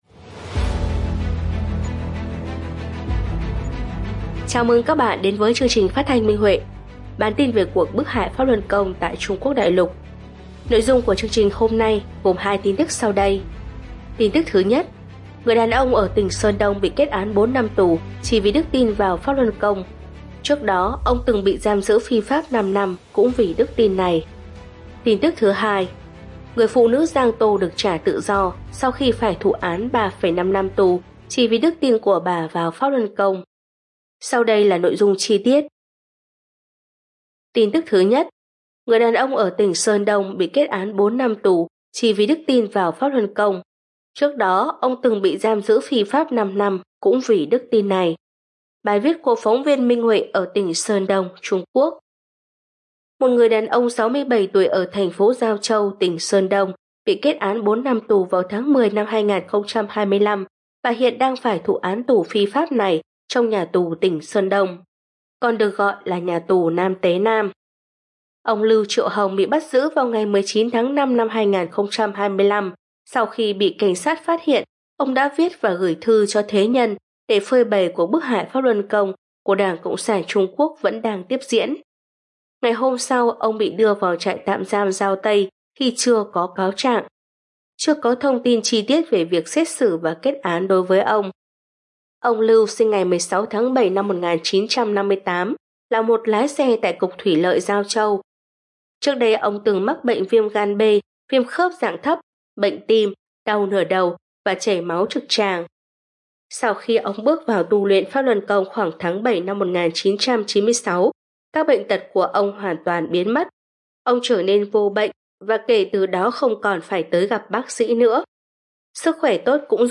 Nội dung của chương trình hôm nay gồm tin tức sau đây: